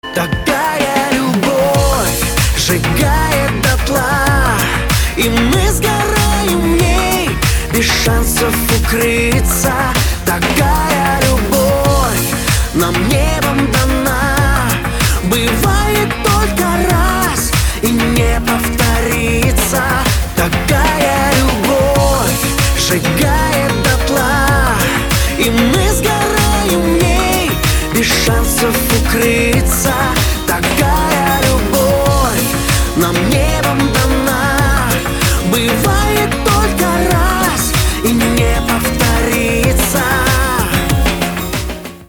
мужской вокал
красивые
dance
спокойные